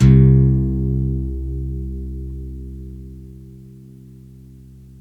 GUITARON 01R.wav